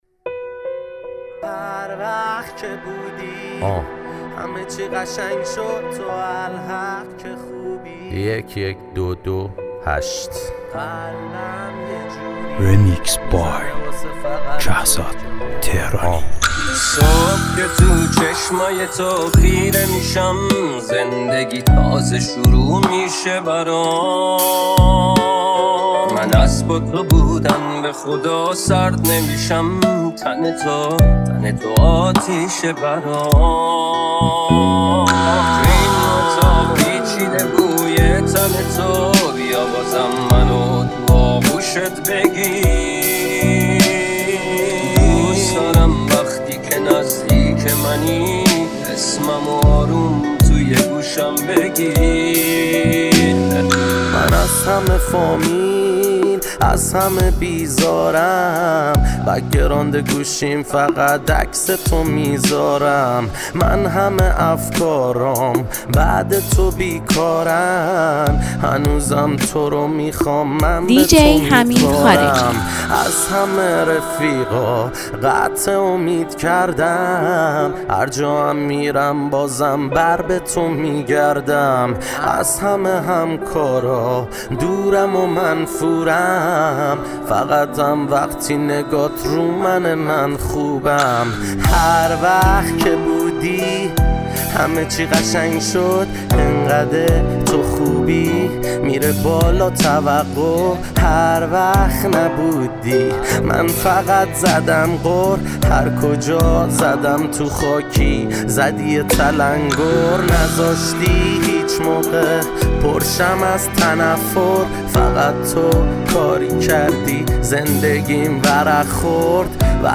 ریمیکس رپ